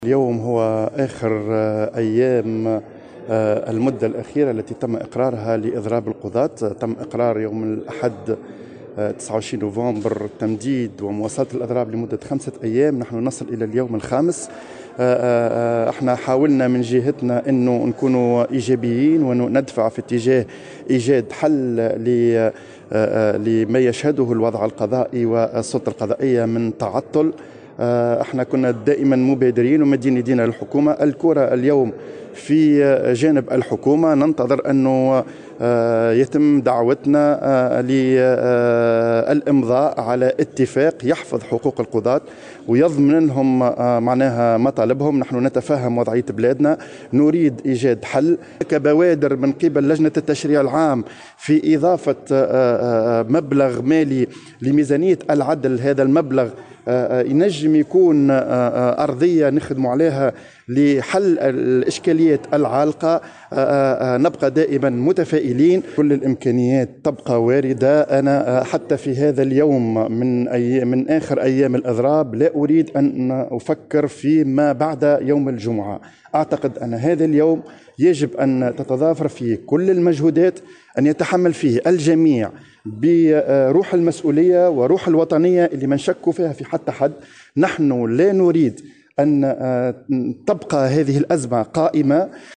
وقال في تصريح اليوم لمراسلة "الجوهرة أف أم"، على هامش اجتماع لوزارة العدل مع لجنة قيادة برنامج دعم إصلاح القضاء، إن الكرة الآن في مرمى الحكومة لإنهاء الأزمة وتمكين القضاة من حقوقهم المشروعة.